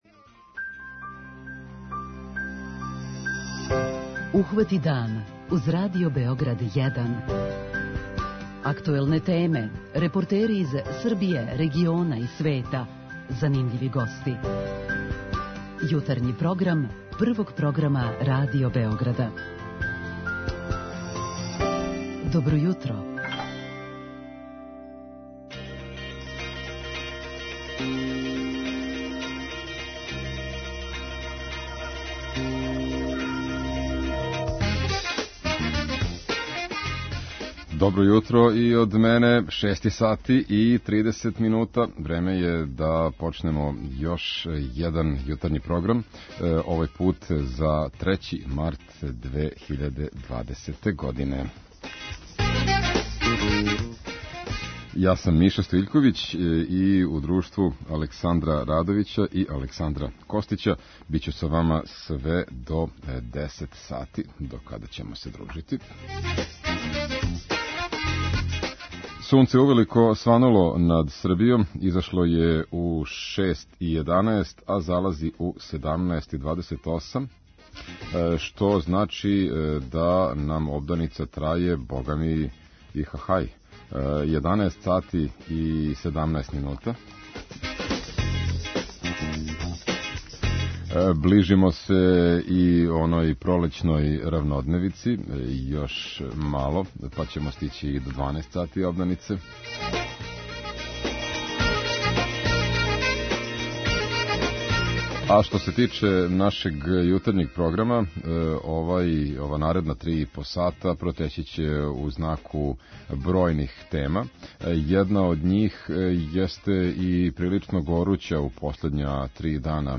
Чућете и репортажу о покладама на Косову, а настављамо да пратимо и збивања с највећег филмског фестивала у Србији - ФЕСТ-а.